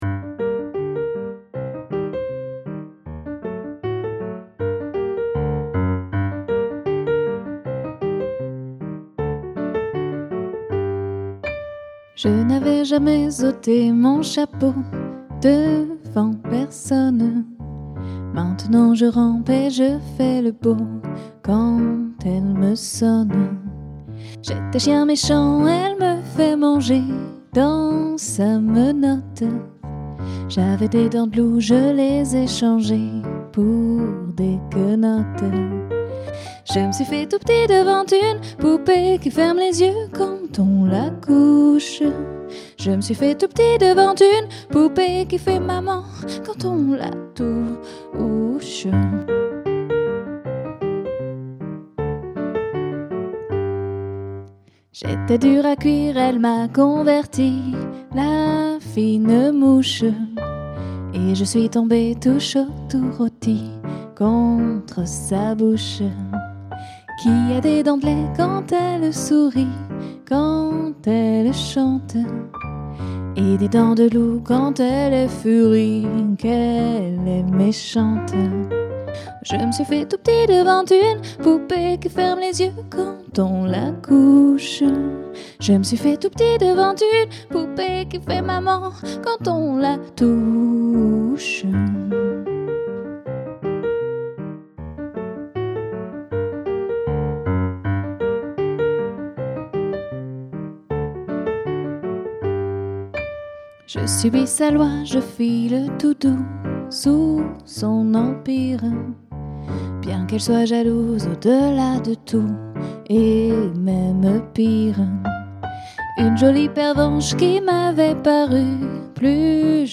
Un duo Piano-Voix au répertoire jazz et variété